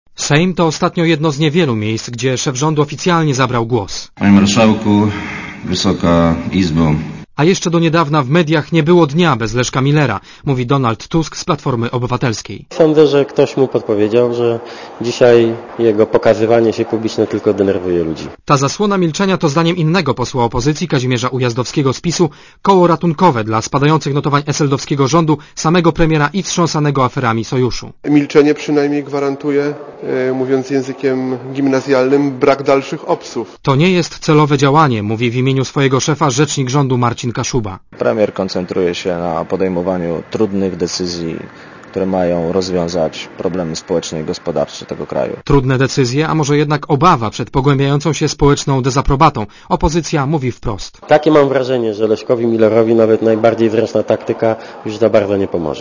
Komentarz audio (220Kb)